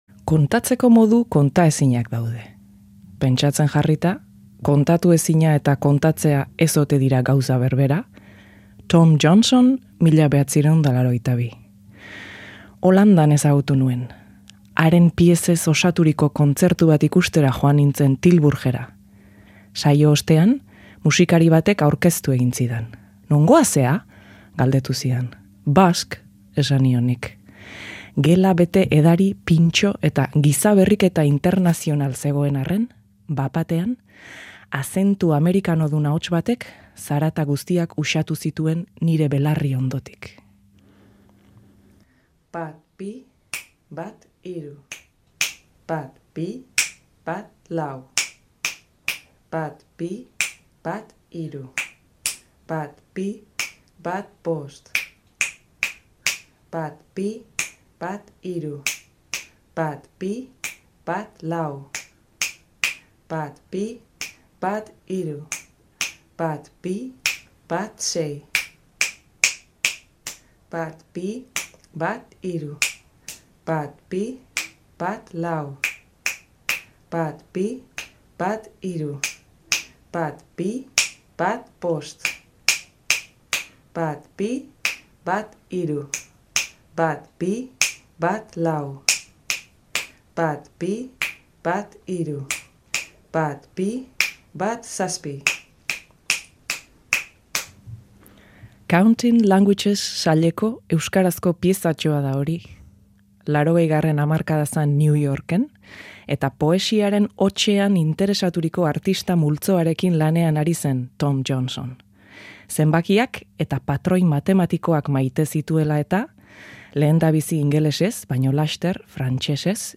Eta kontalariez gain, lotarakoa ere bai. Arratsean.
Kontalariak oraingoan, eta lotarakoak.